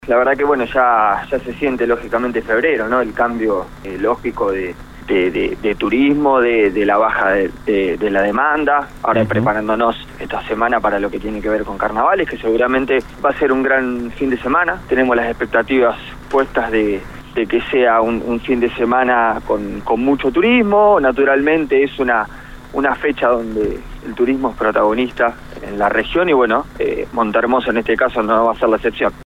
Según confirmó el secretario de Turismo a LU24, Franco Gentile, la localidad ya registra un nivel de reservas del 80 por ciento, una cifra que genera altas expectativas en el municipio.
Finalmente, la entrevista dejó un espacio para la preocupación por el patrimonio histórico de la ciudad: el Faro Recalada.